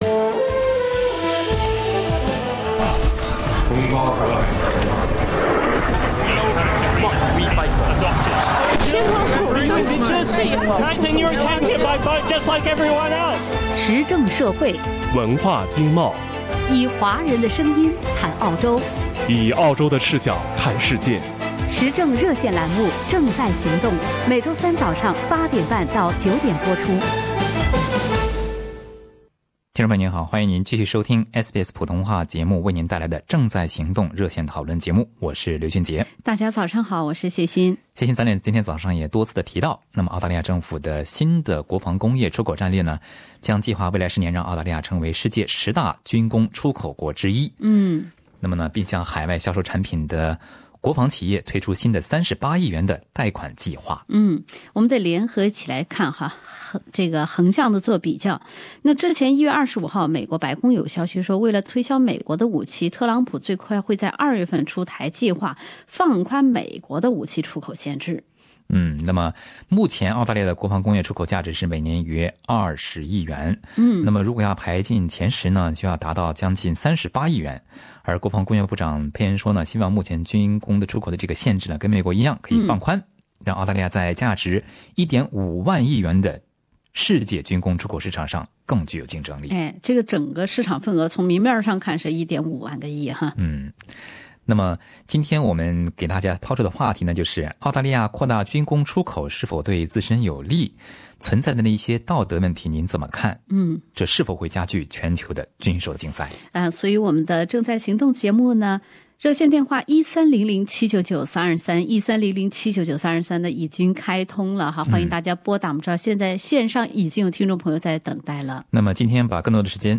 点击图片上的音频，收听听众朋友在本期《正在行动》节目中表达的看法。